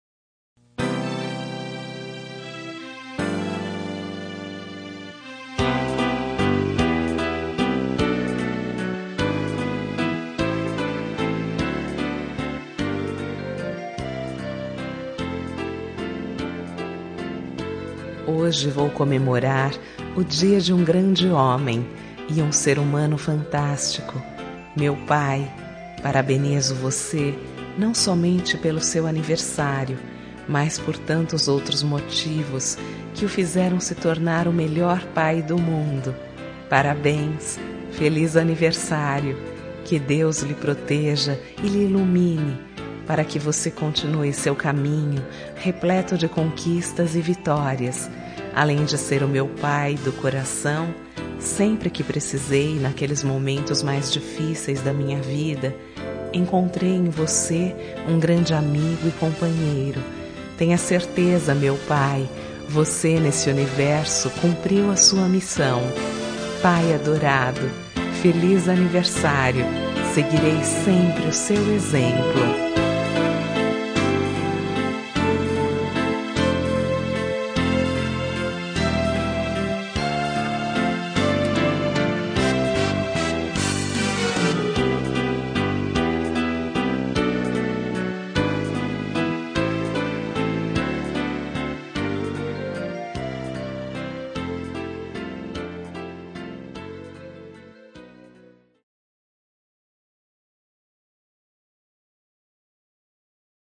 Telemensagem de Aniversário de Pai – Voz Feminina – Cód: 1462